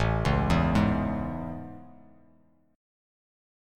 Listen to AM7 strummed